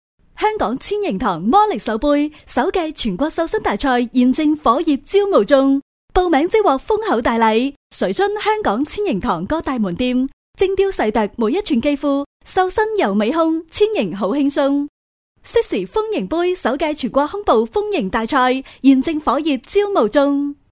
4 女粤23_广告_促销_纤盈堂_激情 女粤23
女粤23_广告_促销_纤盈堂_激情.mp3